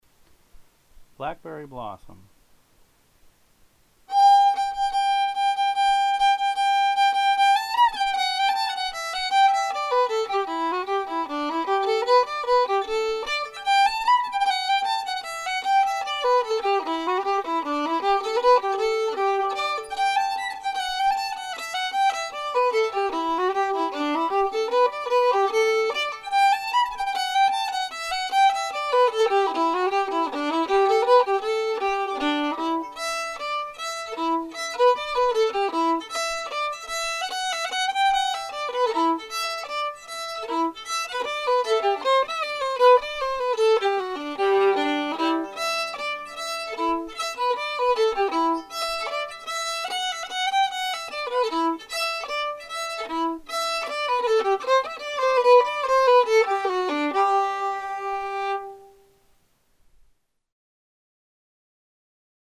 Set 1: Songs 1-60 (Basic Arrangements)